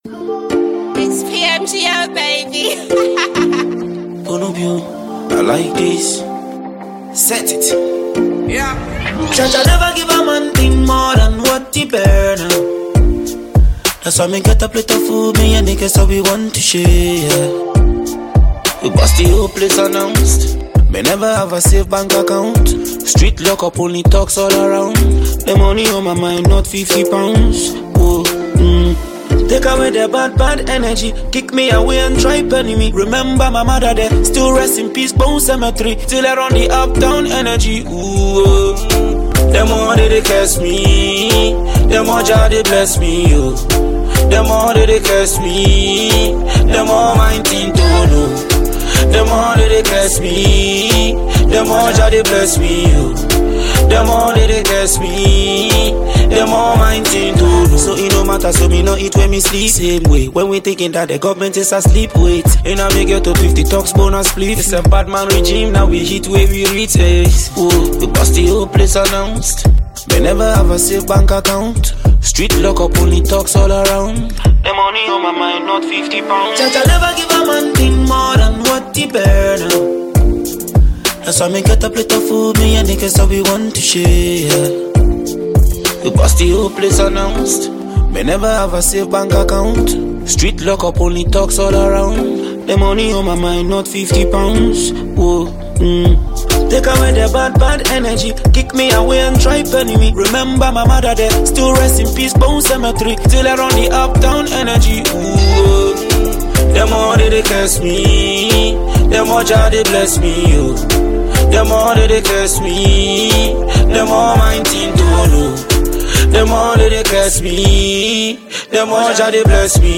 Versatile Ghanaian rapper